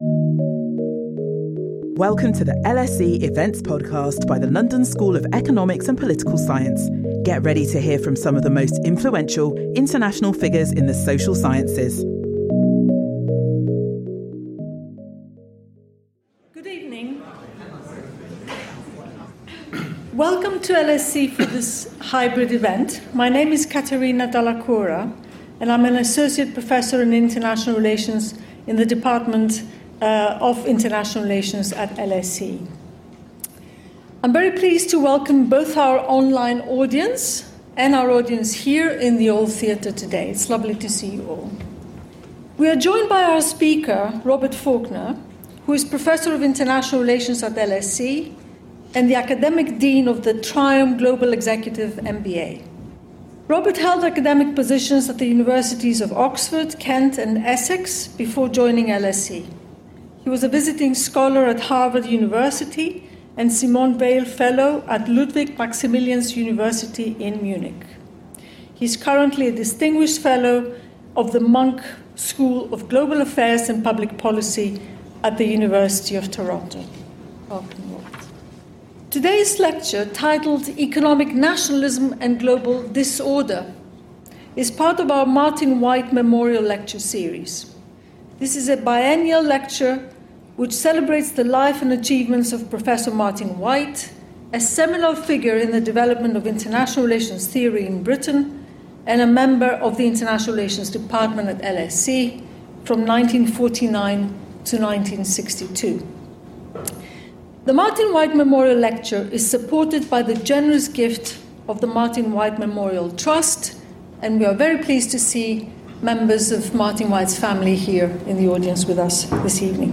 The lecture will be based on his new co-authored book, The Market in Global International Society: An English School Perspective on International Political Economy.